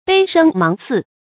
背生芒刺 注音： ㄅㄟˋ ㄕㄥ ㄇㄤˊ ㄘㄧˋ 讀音讀法： 意思解釋： 猶言芒刺在背。